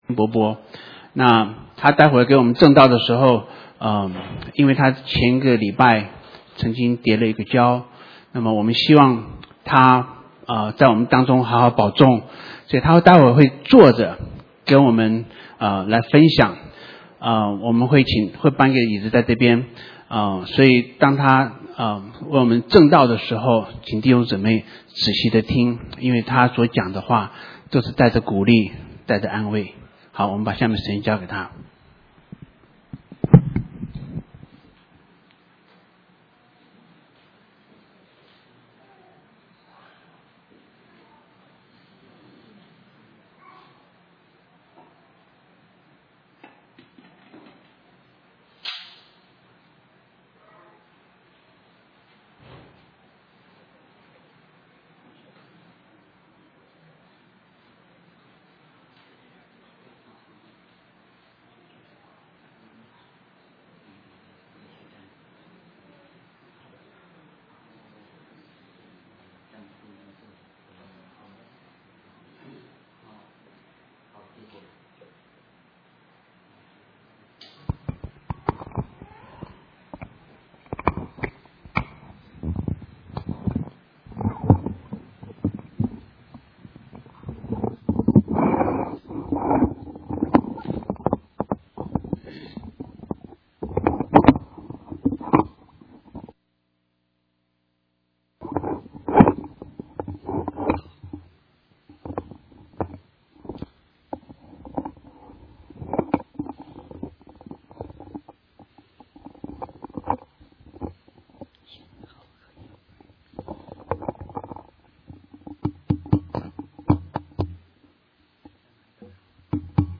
Mandarin Sermons